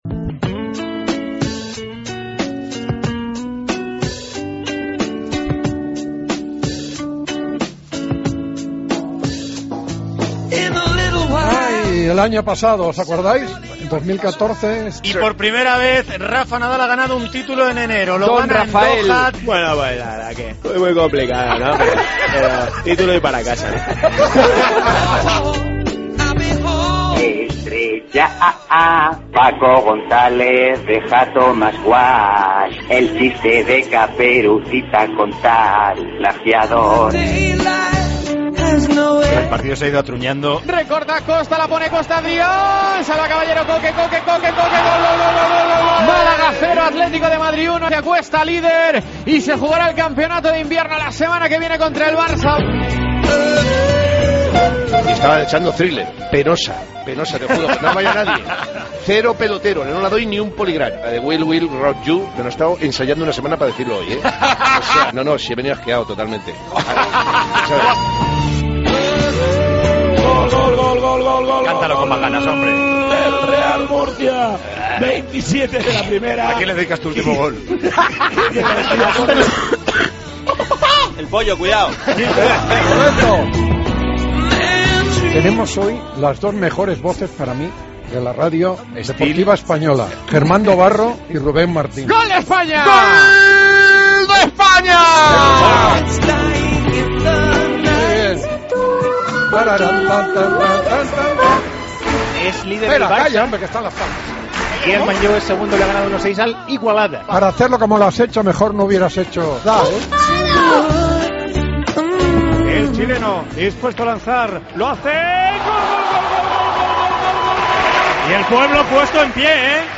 Primera jornada del 2014, sigue la lucha entre Atleti y Barça, tenemos un narrador ronco, Pepe Domingo celebra su propio sorteo de Copa...
Con Paco González, Manolo Lama y Juanma Castaño